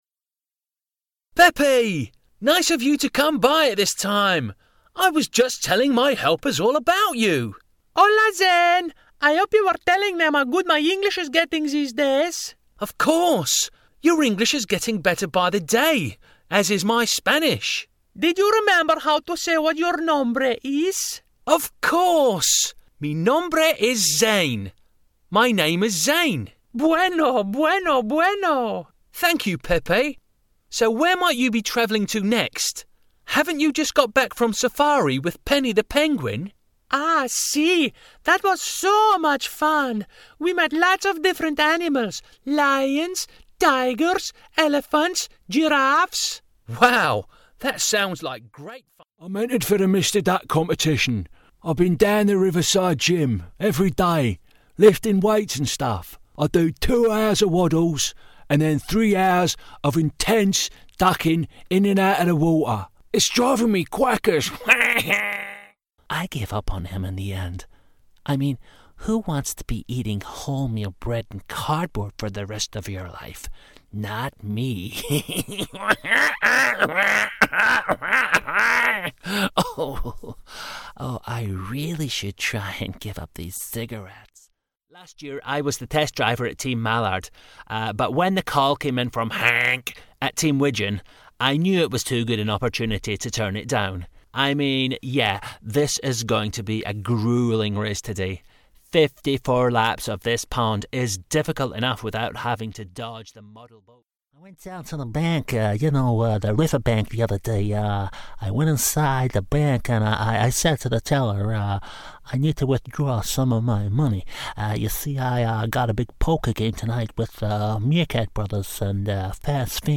Character Voices
A small selection of some characters I have voiced recently.